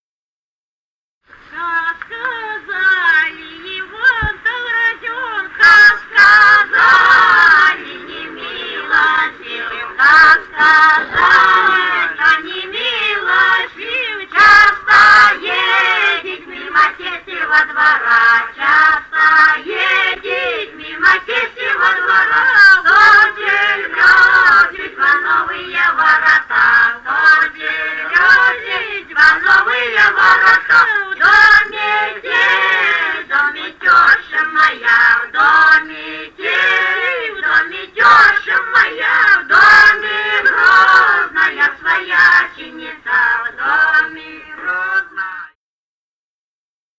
Записали участники экспедиции